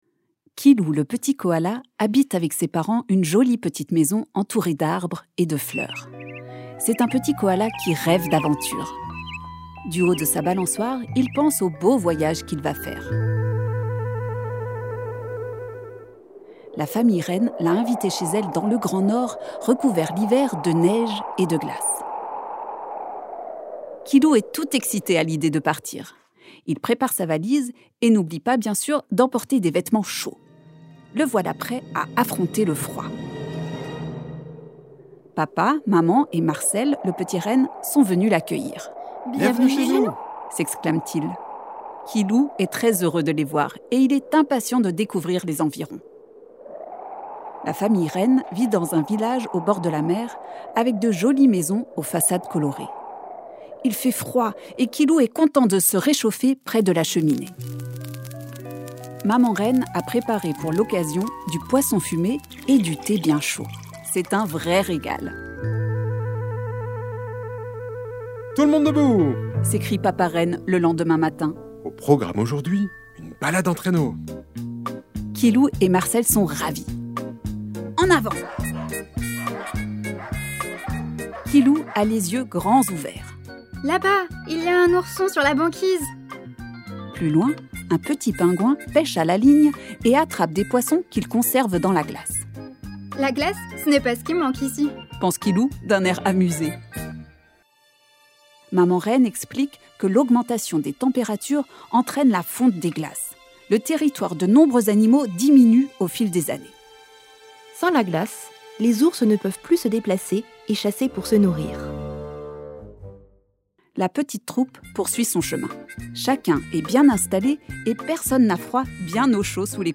Kilou, le petit koala est une série d’histoires pour parler de l’environnement aux enfants dès l’âge de trois ans.
Studio son : CSC l’Escale